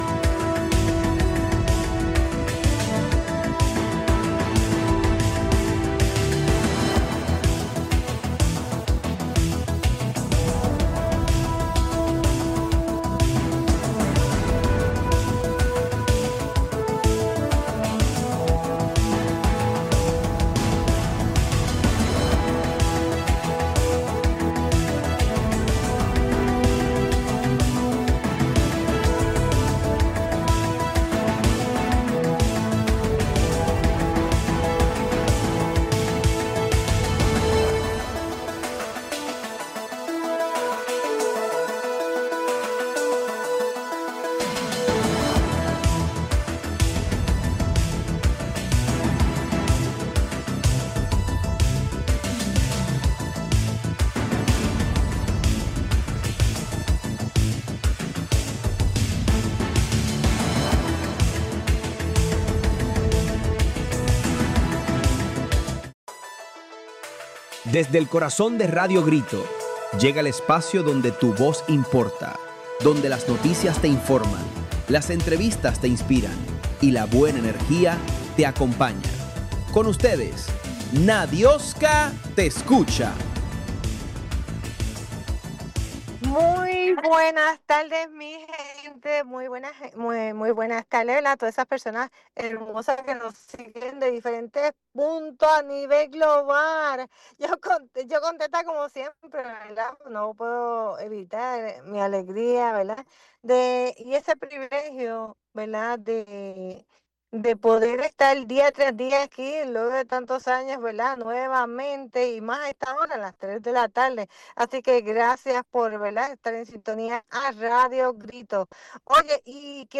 Una entrevista necesaria, informativa y llena de herramientas para proteger vidas.